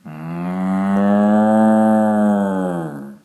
cow-moo-2.ogg